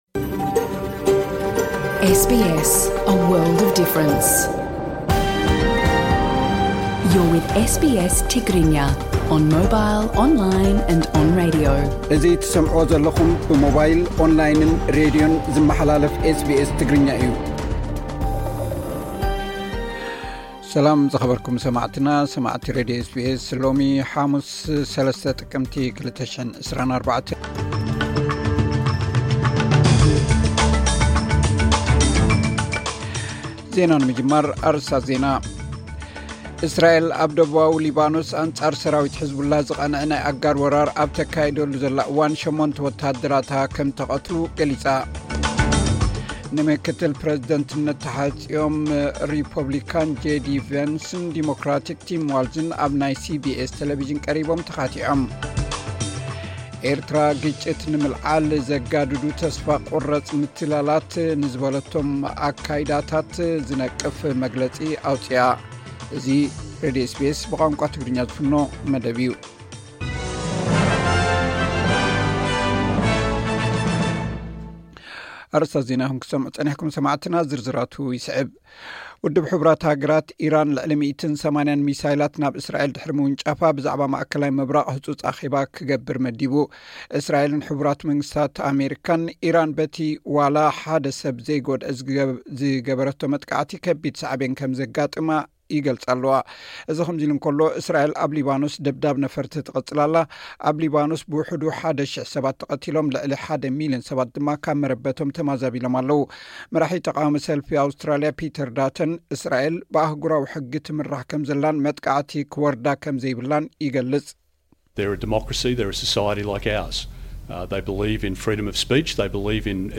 ዕለታዊ ዜና ኤስ ቢ ኤስ ትግርኛ (03 ጥቅምቲ 2024)